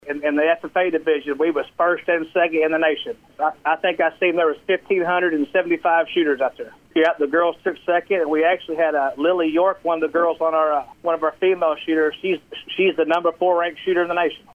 sports update
We asked coach